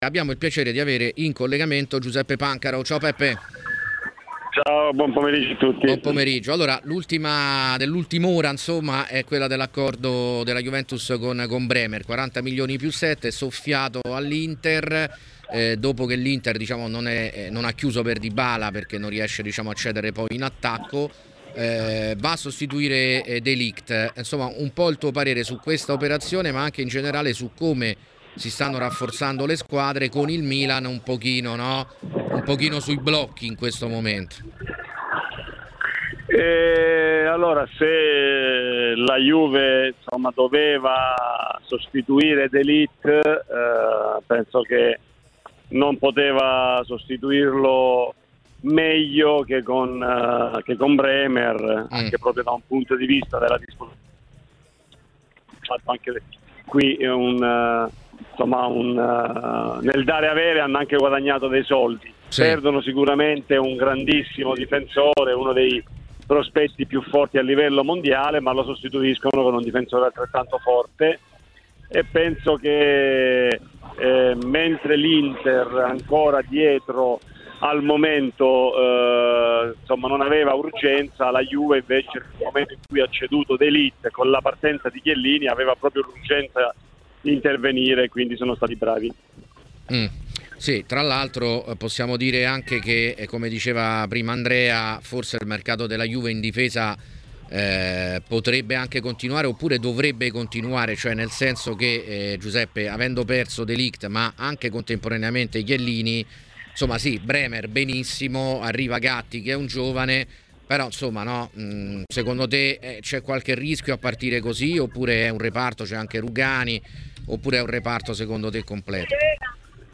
Giuseppe Pancaro, ex difensore tra le altre della Lazio e Torino oltre che della Nazionale italiana, ha parlato ai microfni di Tmw Radio.